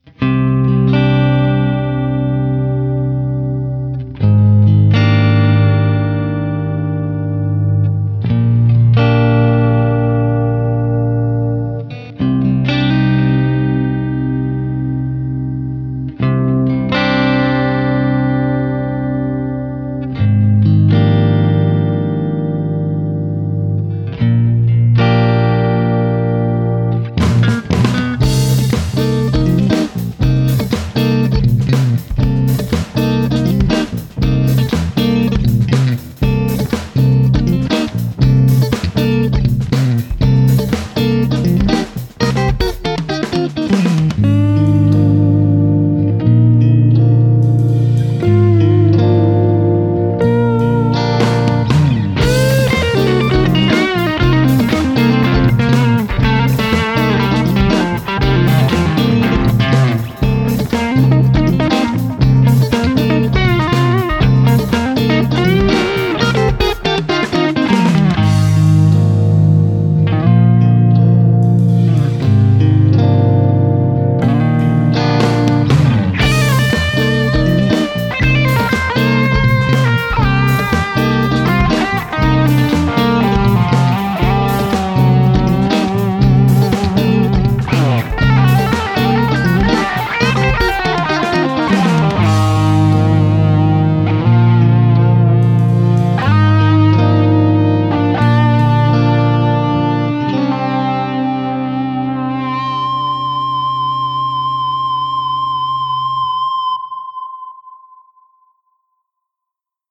Here are a few explorations recorded this afternoon with the PRS Fiore Amaryllis received at the office yesterday – a very inspiring instrument!
Of course, since it’s Mark Lettieri’s guitar, it had to get funky at some point!
• Bass guitar: Warwick Streamer, processed by Axiom‘s Amp simulation.
• I am not playing all drums part this time (only breaks & cymbals on slow parts).
For guitar tones, I used my “live” setup, with my favorite clean amp (“Texas Comp” factory preset) and some built-in virtual pedals in front of it (Compressor, Overdrive or the “Crunch Pressor”), and a Tape Ping Pong delay factory preset from Late Replies:
To get some more bite for the last part of the guitar solo, I used another factory amp (Lead channel), with the Crunch Pressor that acts as a bright boost with a bit of crunch: